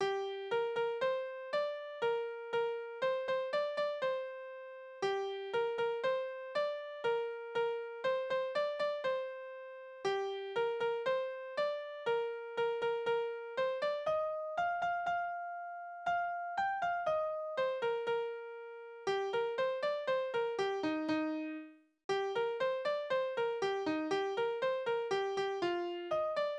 Kinderspiele
Tonart: g-Moll
Taktart: 2/4
Tonumfang: große Dezime
Anmerkung: - Refrain ist unvollständig